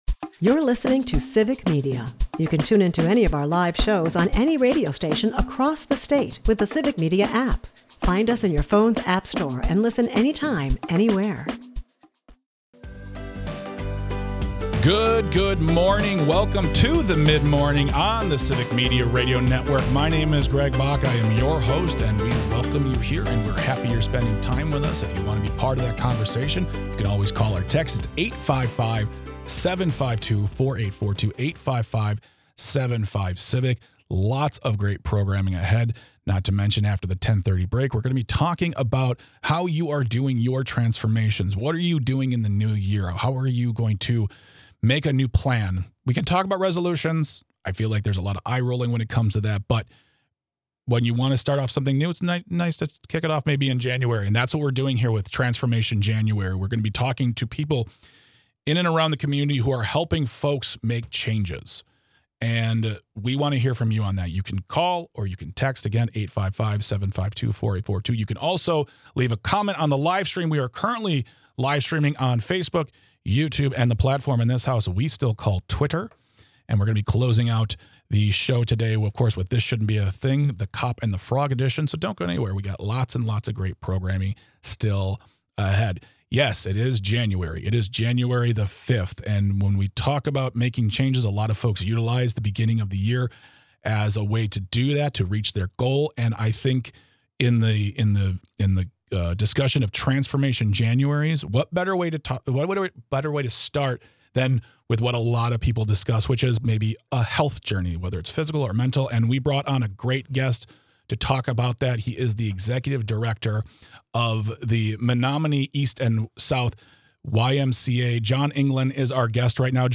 Then we hear from you about your goals for the new year and how we can hold each other accountable.